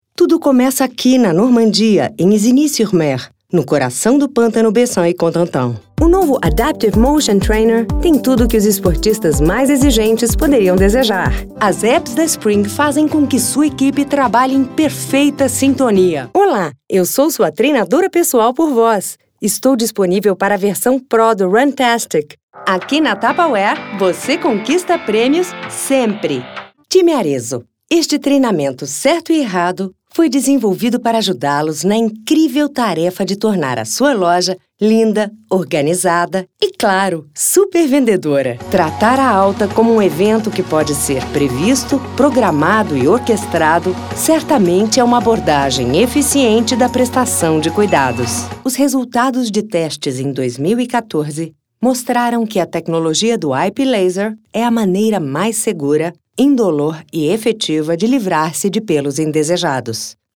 Brazilian native VO talent and actress with +20 years experience, records in BR Portuguese and English w/accents.
Sprechprobe: Industrie (Muttersprache):